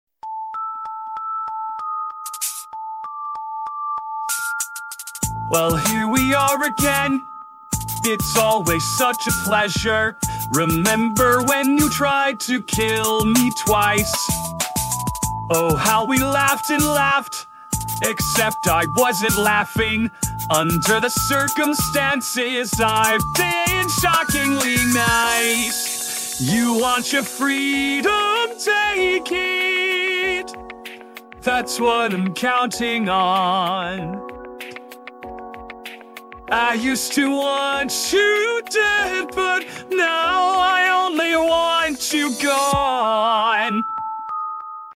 Vox AI Cover